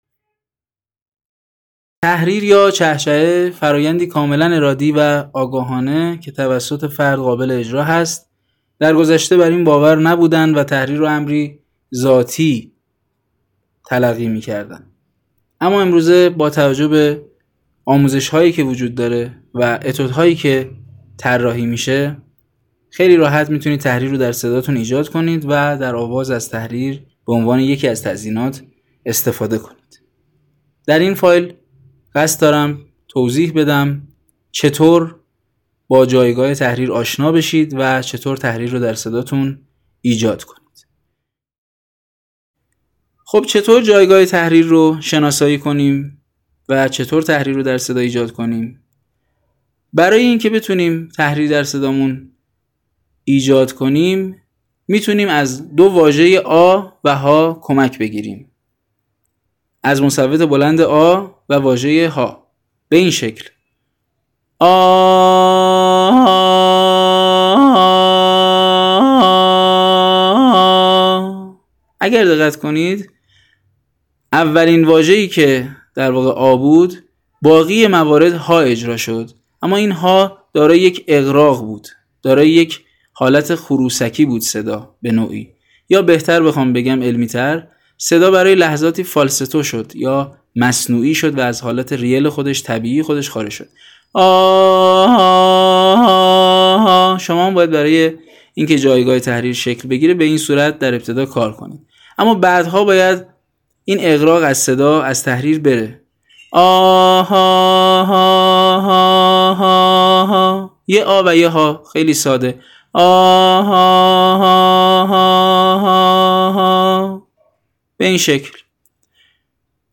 توضیح مفهوم تحریر همراه با تمرین
تحریر در اصطلاح عبارت است از صوتی آهنگین که در اثر عبور هوا و ارتعاش تارهای صوتی به وجود می آید ، تحریر از ایجاد یک شکست در صدا حاصل شده ، به تعبیر دیگر تحریر حاصل انقطاع و اتصال تارهای صوتی است.
تحریر یکی از انواع تزئینات آوازی است.